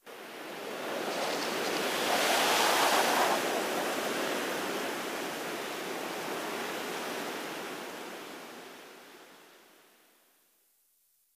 windwhistle4.ogg